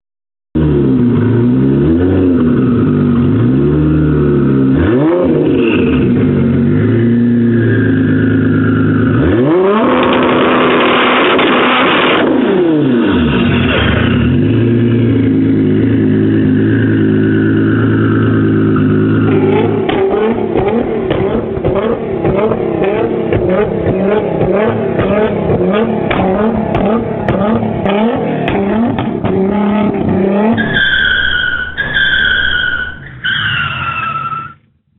الالات واصوات